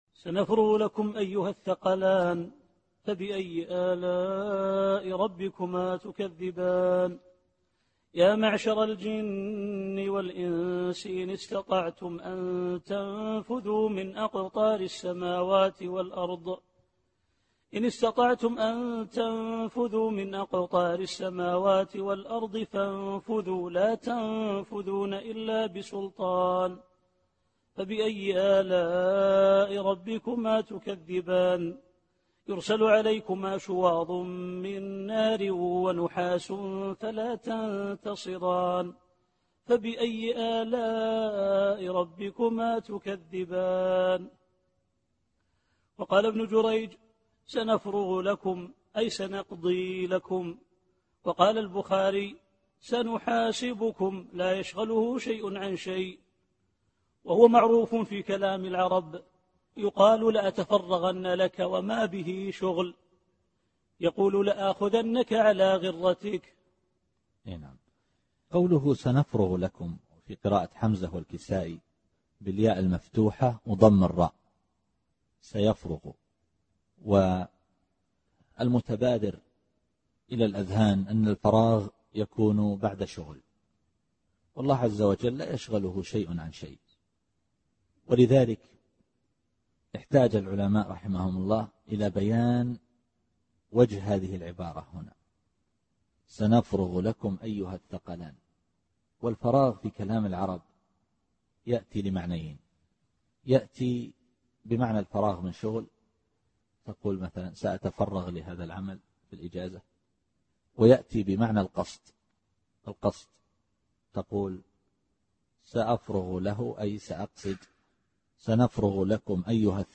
التفسير الصوتي [الرحمن / 32]